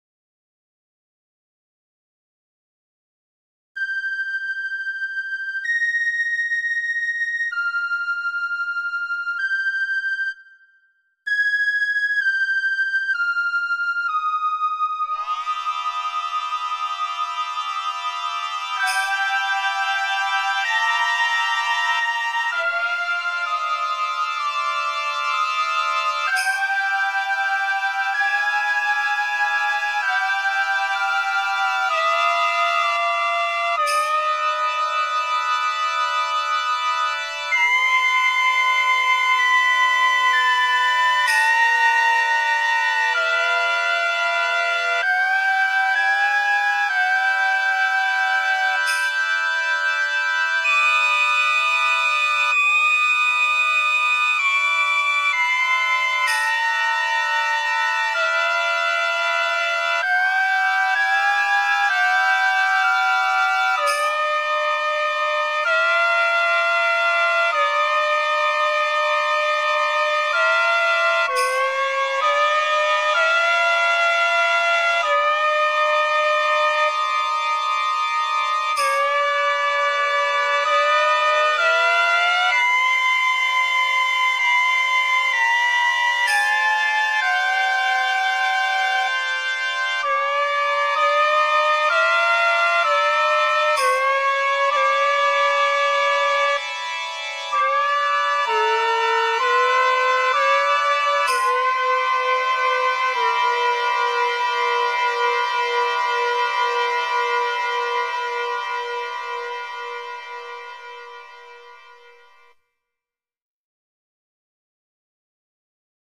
雅楽風の曲で、平安京の風景の一部が見えればいいなと思いアレンジ。